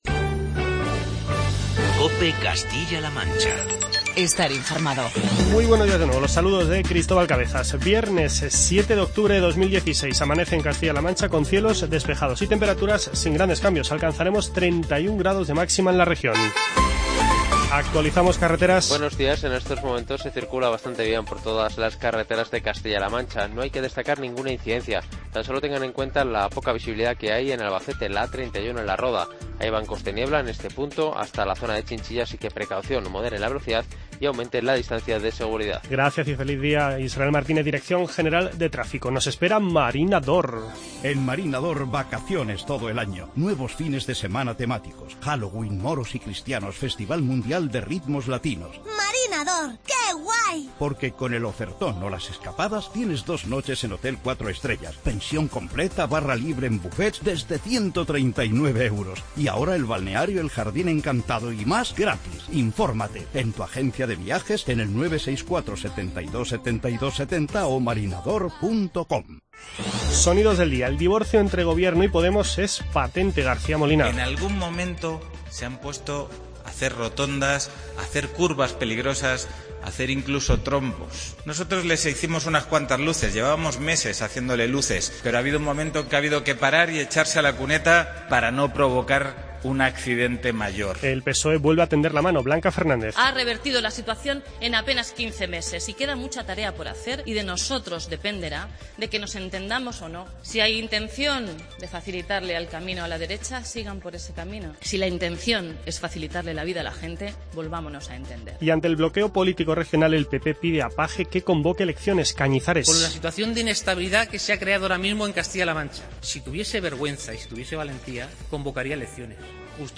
Escuche en nuestras "Voces de los Protagonistas" las palabras de José García Molina, Blanca Fernández, Francisco Cañizares, Elena de la Cruz y del arzobispo de Toledo, Braulio Rodríguez.